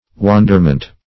Wanderment \Wan"der*ment\, n. The act of wandering, or roaming.
wanderment.mp3